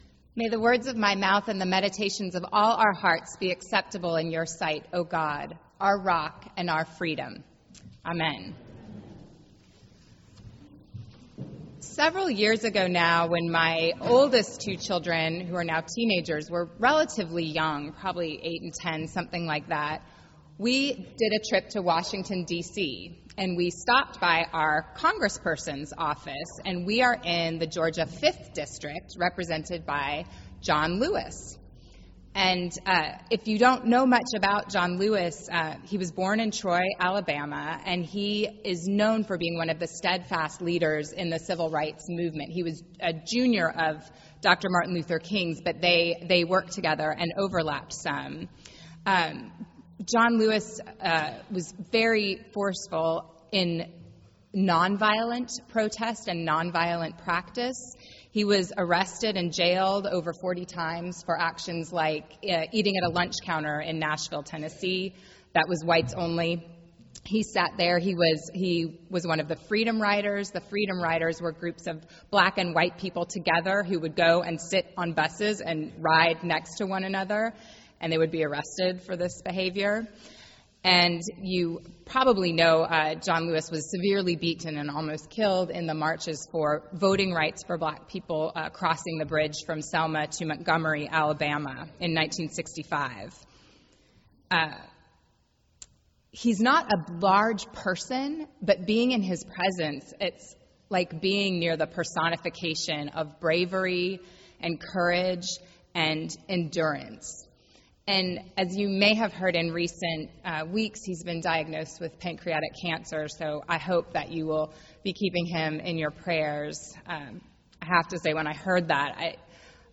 Sermon - The Second Sunday after the Epiphany